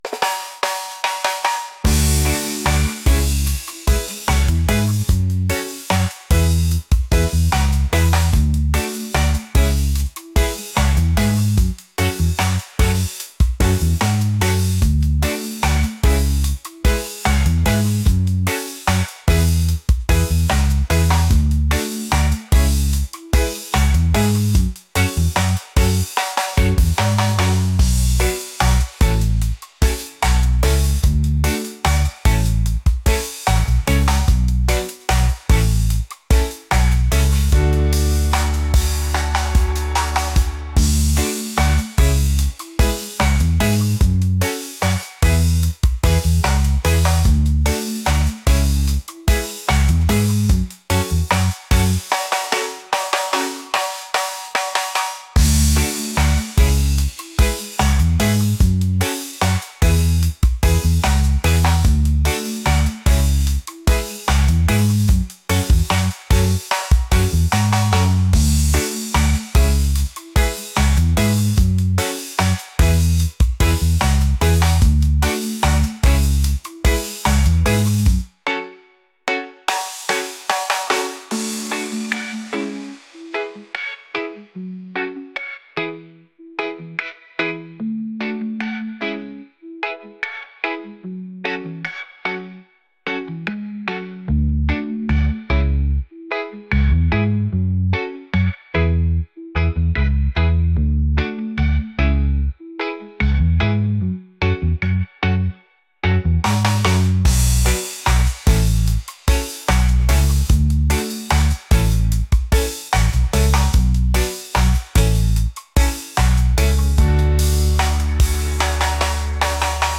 laid-back | upbeat | reggae